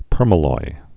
(pûrmə-loi, pûrm-ăloi)